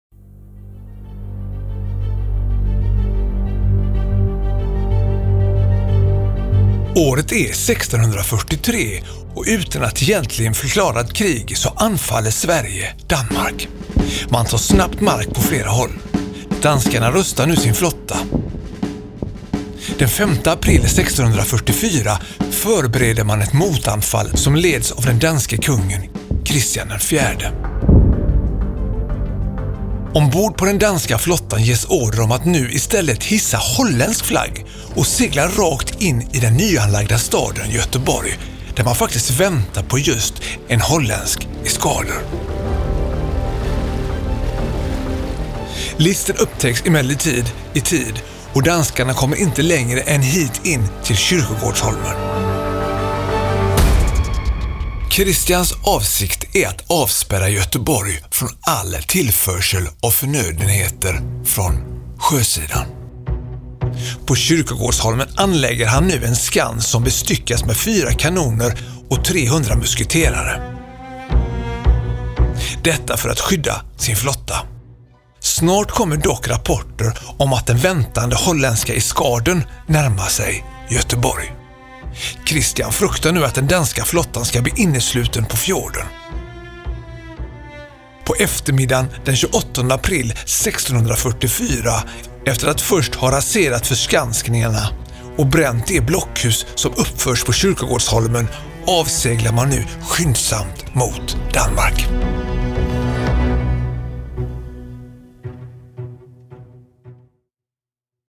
Med denna audioguide får du följa med in bakom murarna på en plats där krig, fredsförhandlingar och fängelseliv lämnat djupa spår.